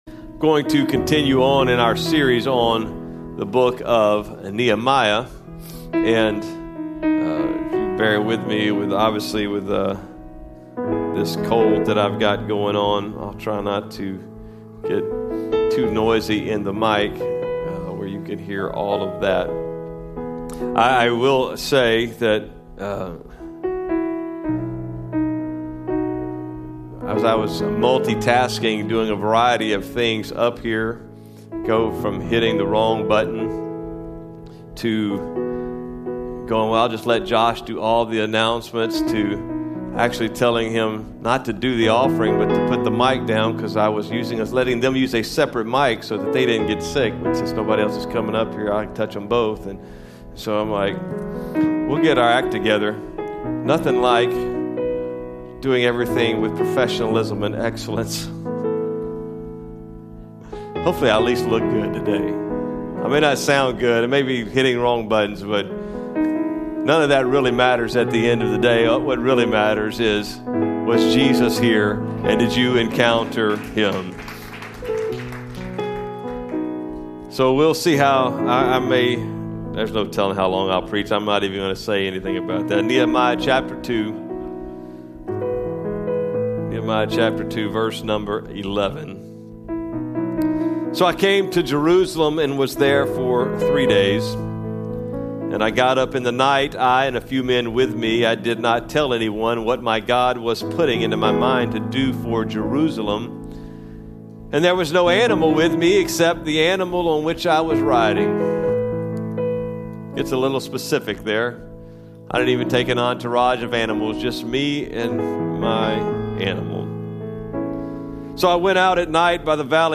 Podcast for Cross Church KC Sermons
Cross Church KC - Sermons